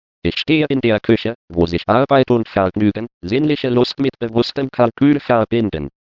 Die folgenden Beispiele desselben Satzes, der von verschiedenen deutschen Text-to-Speech-Sprachsynthesen generiert wurde, können das illustrieren.
Bsp13: Bell Labs; Bsp14: IKP; Bsp15: TU-Dresden; Bsp16:IMS;